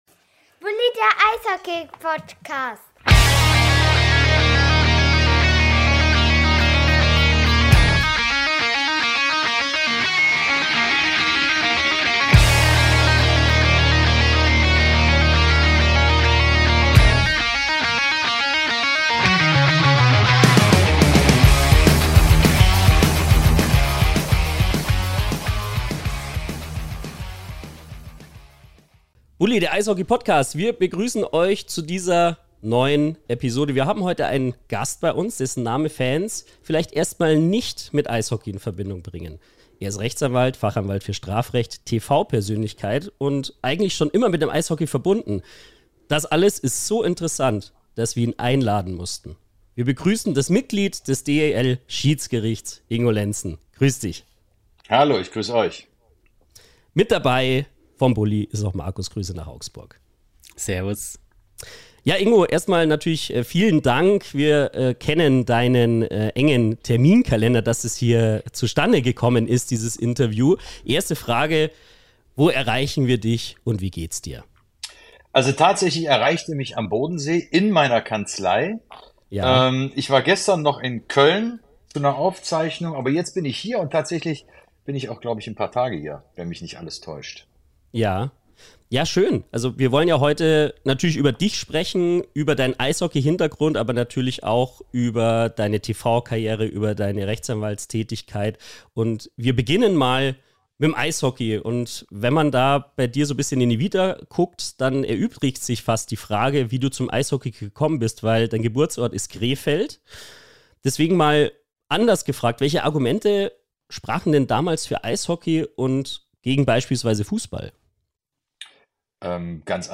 In dieser Episode ist Rechtsanwalt Ingo Lenßen bei uns zu Gast
Gespräch mit Rechtsanwalt Ingo Lenßen